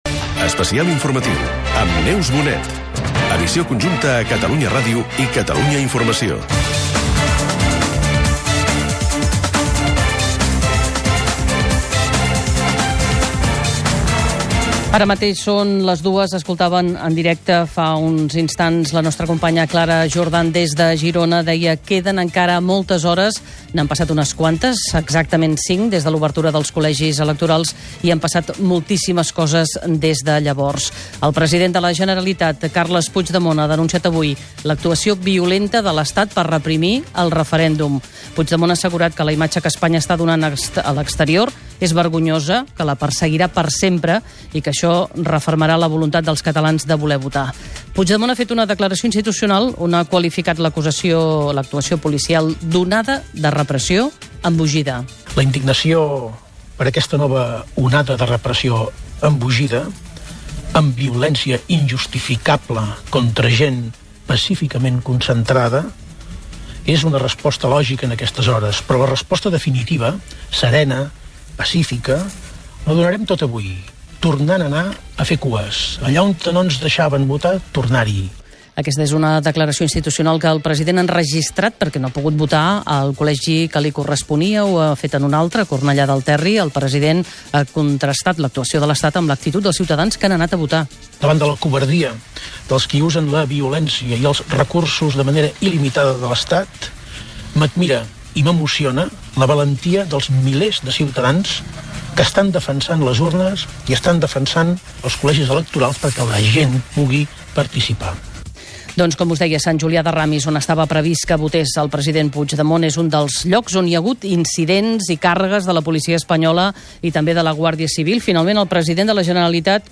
Careta del programa, hora, declaracions dels president de la Generalitat Carles Puigdemont. Resum dels incidents viscuts durant el matí del dia del referèndum de l'1 d'octubre a Girona, terres de l'Ebre, Lleida i Barcelona., Compareixences de la vicepresidenta del Govern espanyol Sáenz de Santamaría i del conseller de la Generalitat Jordi Turull. Gènere radiofònic Informatiu